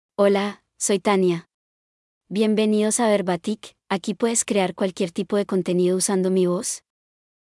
Tania — Female Spanish (Paraguay) AI Voice | TTS, Voice Cloning & Video | Verbatik AI
FemaleSpanish (Paraguay)
Tania is a female AI voice for Spanish (Paraguay).
Voice sample
Listen to Tania's female Spanish voice.
Tania delivers clear pronunciation with authentic Paraguay Spanish intonation, making your content sound professionally produced.